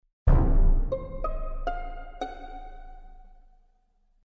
4. Репорт